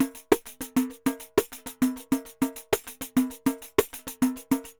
Pandeiro 2_Samba 100_5.wav